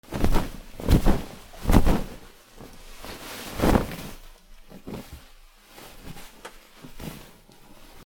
シーツを広げる 布